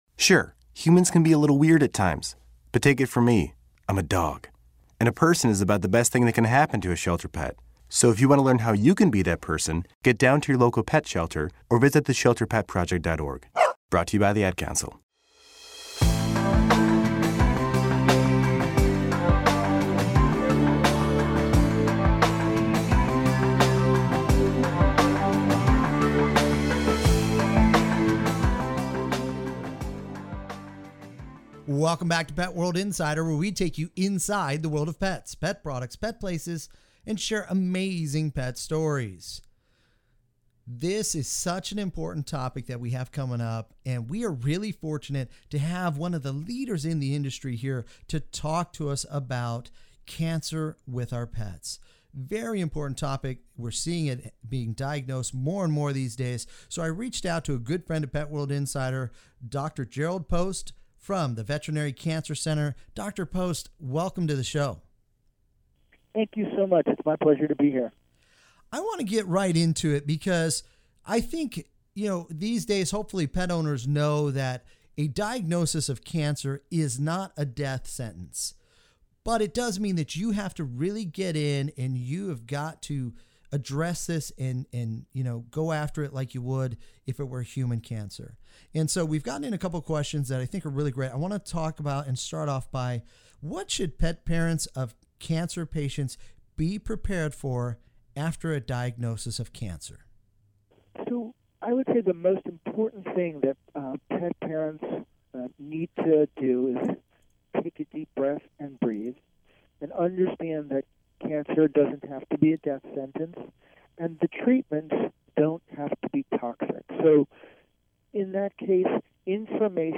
Enjoy this Pet World Radio Segment in case a station near you does not currently carry Pet World Radio on the EMB or CRN networks!